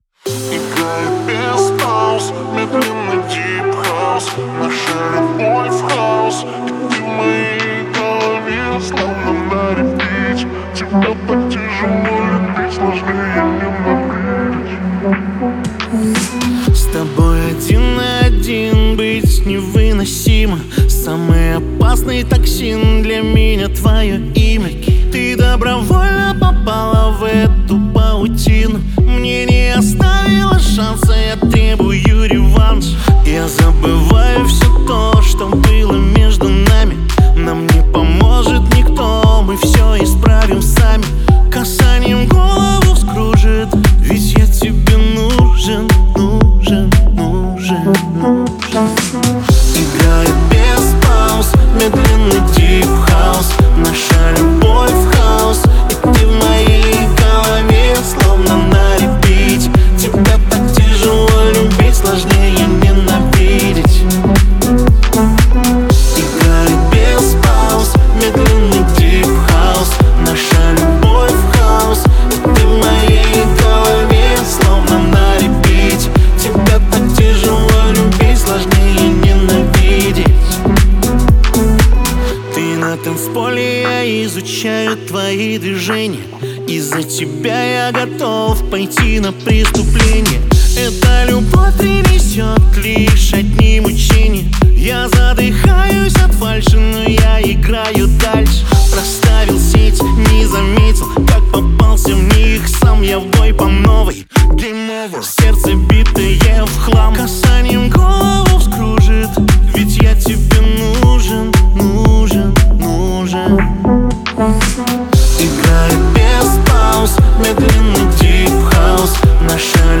ki_Vverkh___Deep_House.mp3